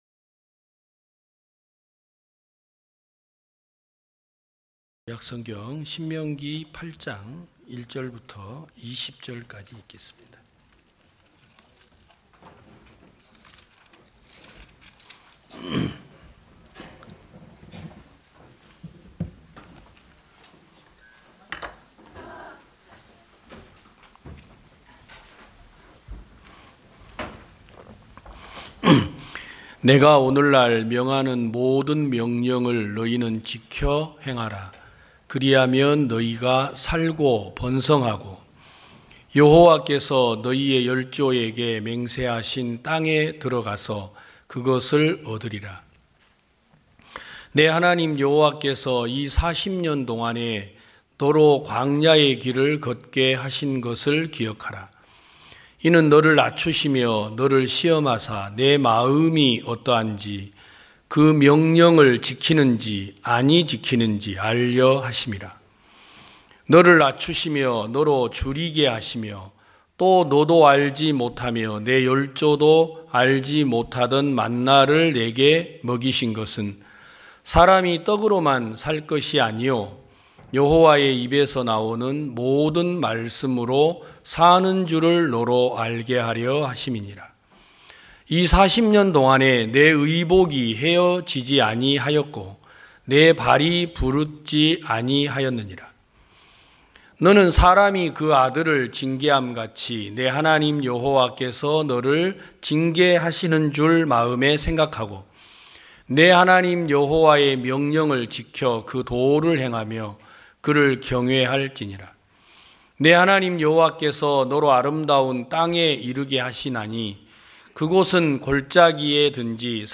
2022년 12월 04일 기쁜소식부산대연교회 주일오전예배
성도들이 모두 교회에 모여 말씀을 듣는 주일 예배의 설교는, 한 주간 우리 마음을 채웠던 생각을 내려두고 하나님의 말씀으로 가득 채우는 시간입니다.